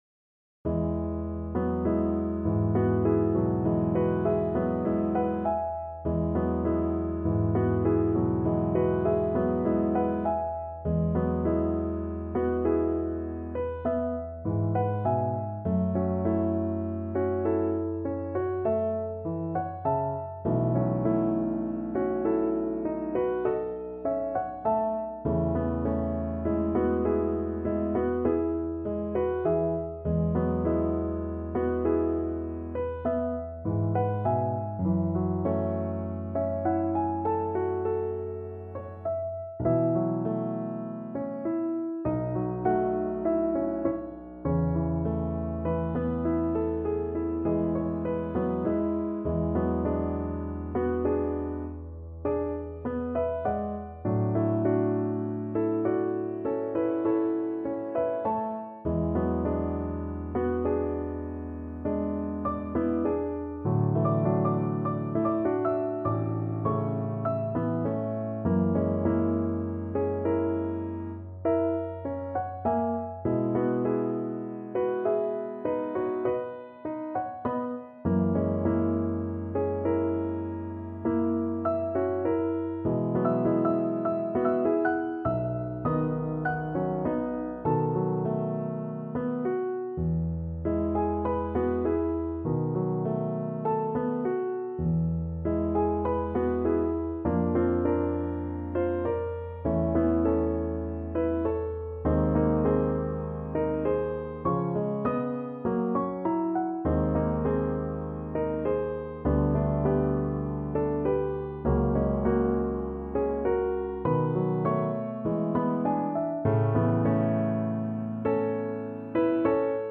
Play (or use space bar on your keyboard) Pause Music Playalong - Piano Accompaniment Playalong Band Accompaniment not yet available transpose reset tempo print settings full screen
E major (Sounding Pitch) (View more E major Music for Flute )
Lento =50
Classical (View more Classical Flute Music)